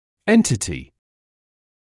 [‘entɪtɪ][‘энтити]отдельное явление; организация